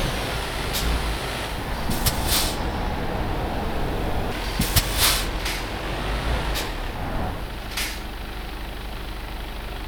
Index of /server/sound/vehicles/lwcars/truck_2014actros
slowdown_slow.wav